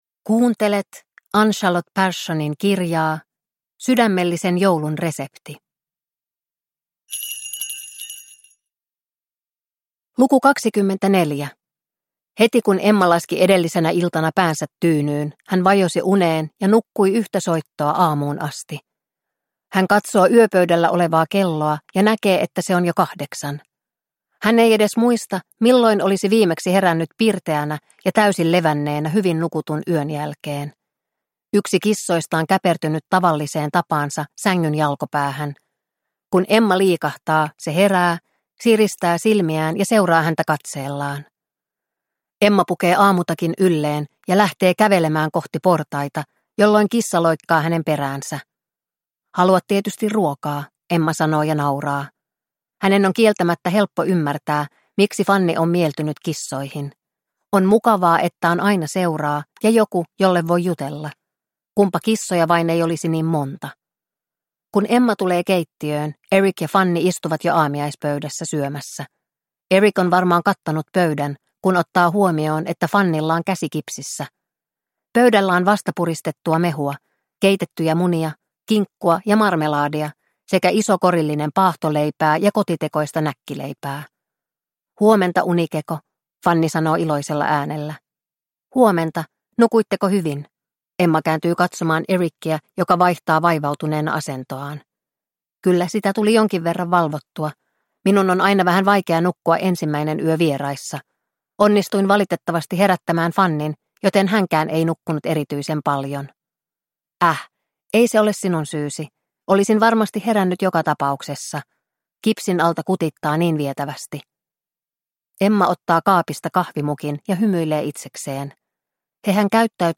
Sydämellisen joulun resepti - Luukku 24 – Ljudbok – Laddas ner